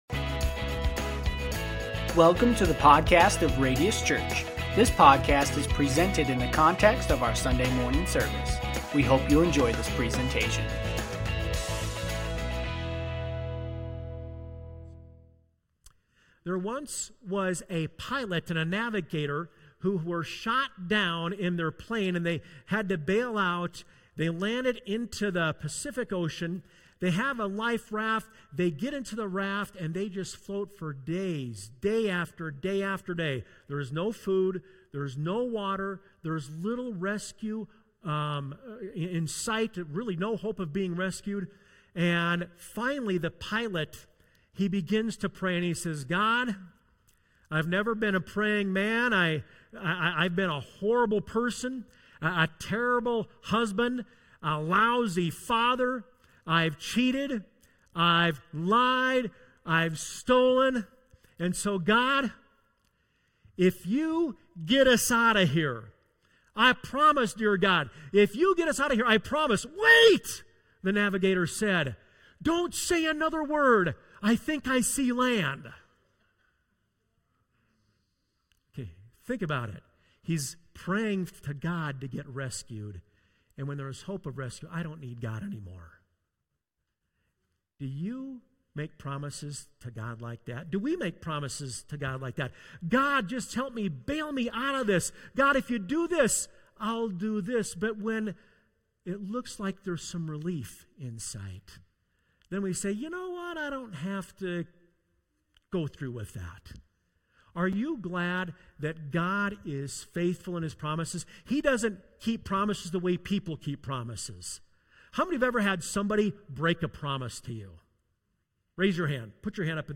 Sermons | Radius Church